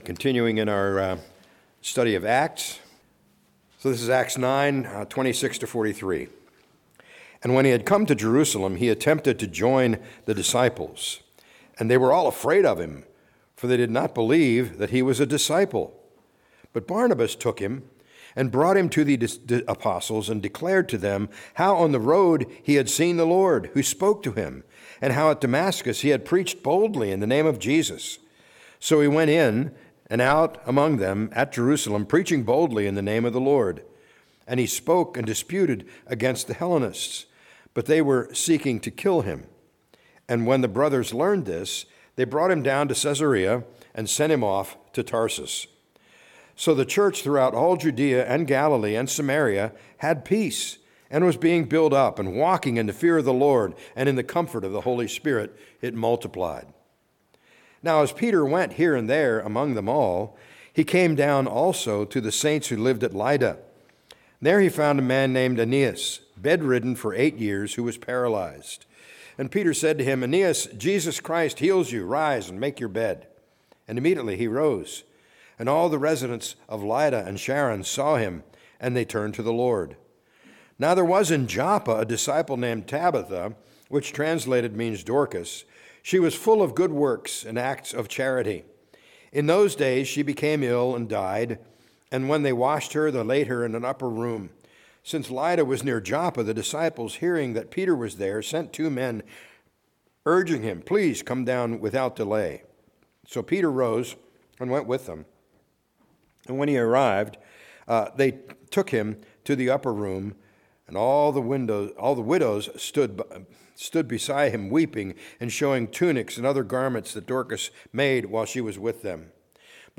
A message from the series "Advent 2025."